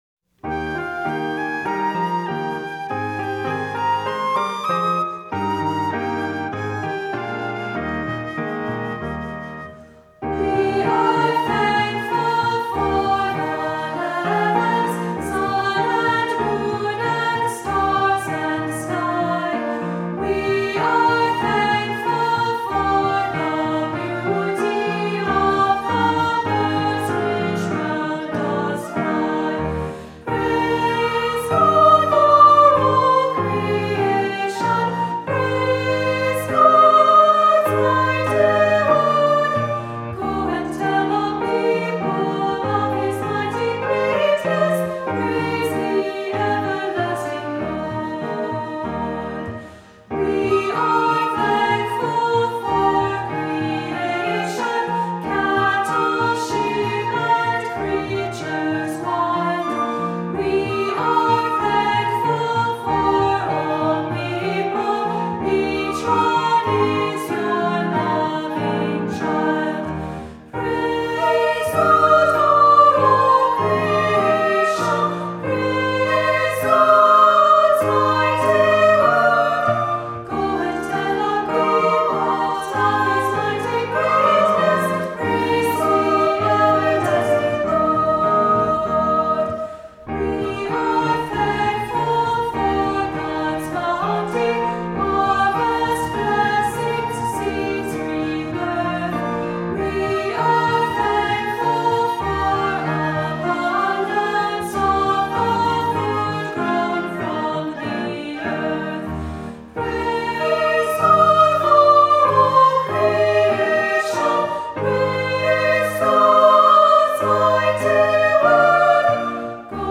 Voicing: Unison ChildrenÕs Choir; Descant